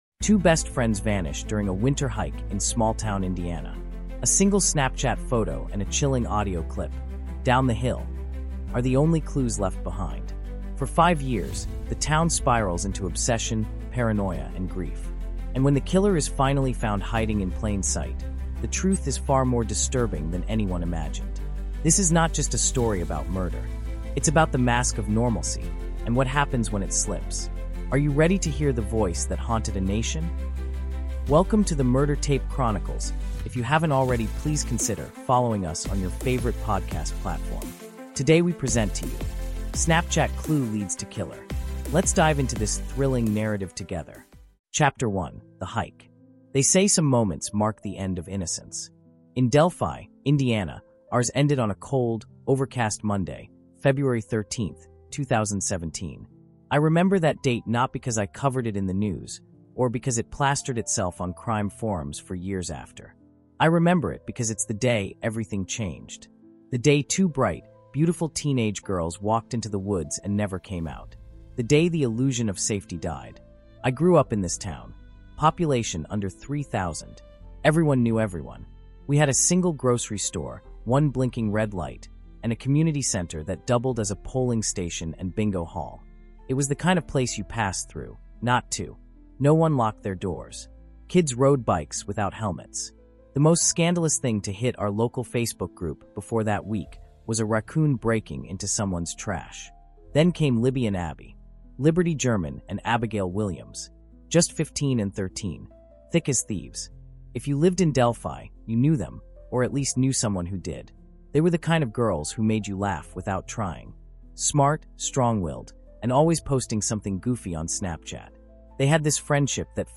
Snapchat Clue Leads to Killer is a raw, intimate true-crime audiobook that takes you deep into the haunting 2017 double homicide of two teenage best friends in Delphi, Indiana. Told from the first-person perspective of a local journalist whose life was shattered by the case, this five-chapter emotional thriller explores obsession, betrayal, and the shocking realization that the killer had been hiding in plain sight for years.
With immersive narration, real emotional depth, and a cinematic structure, this isn’t just a retelling—it’s a descent into the human psyche when justice takes too long.